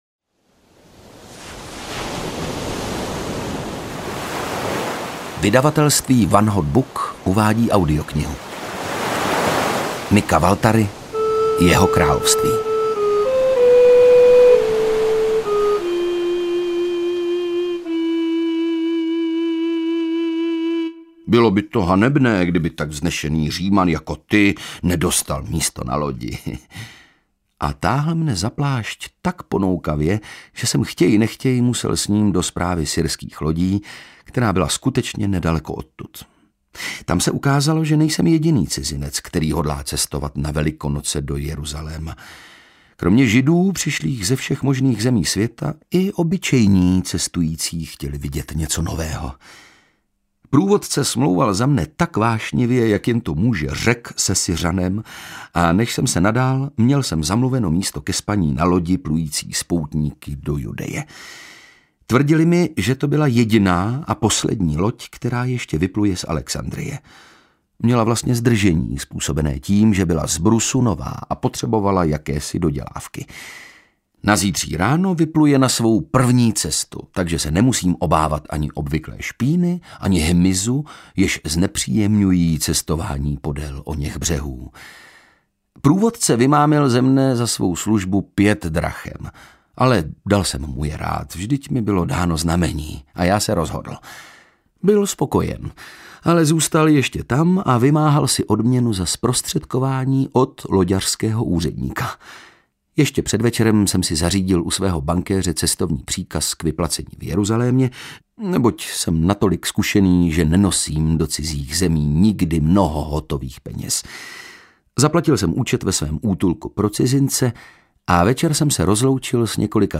Jeho království audiokniha
Ukázka z knihy
• InterpretOndřej Brousek